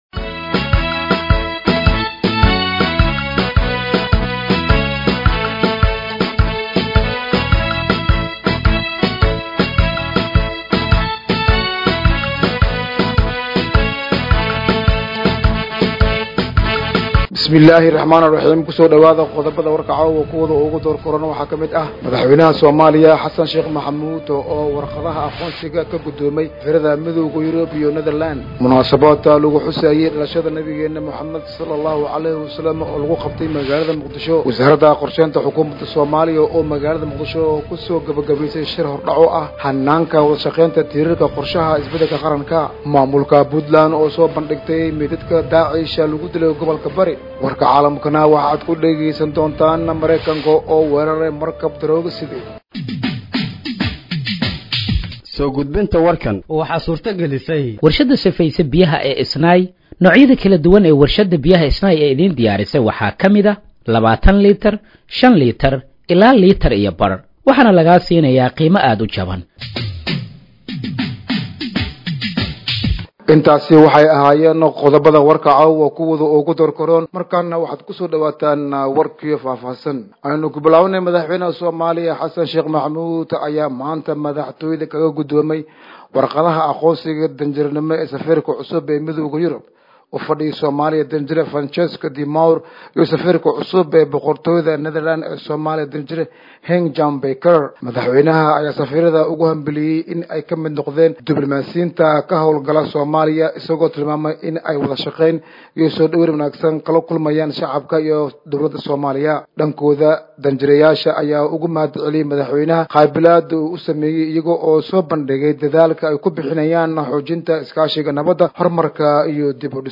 Dhageeyso Warka Habeenimo ee Radiojowhar 03/09/2025